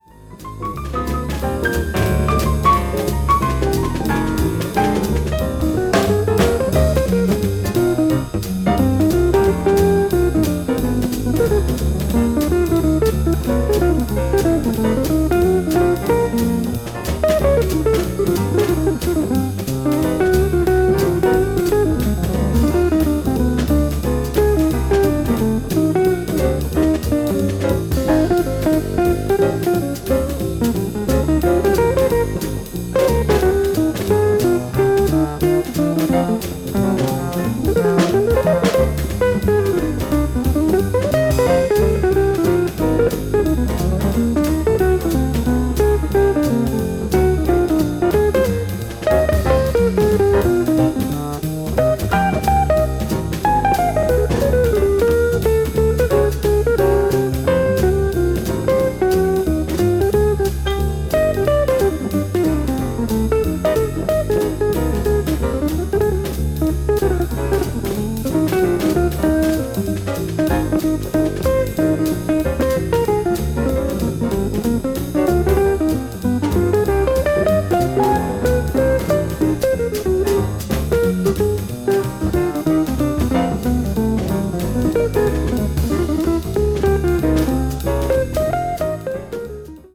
pianist
guitar
bass
drums
modal jazz   modern jazz   post bop